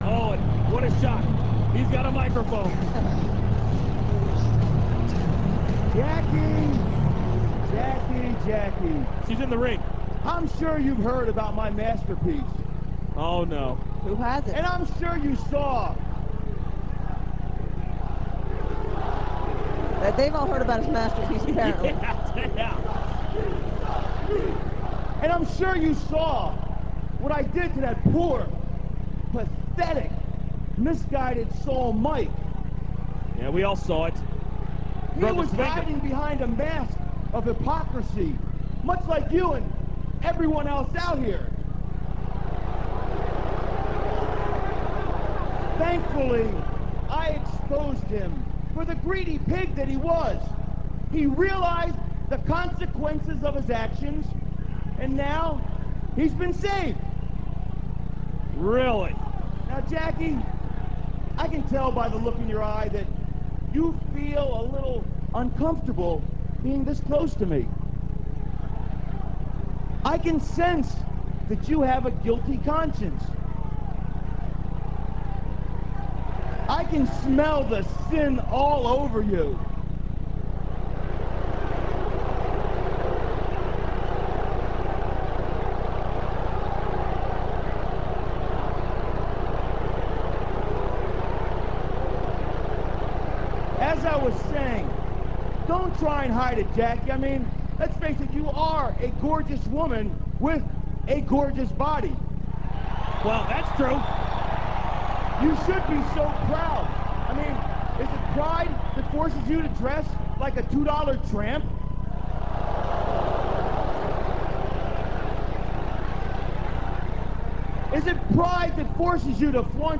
raven85.rm - This clip comes from WWE HEAT - [12.22.02]. Raven unveils the second step of his 'masterpiece' - Pride - by confronting Jacqueline with this sin. The Hurricane then confronts Raven over his zealotry.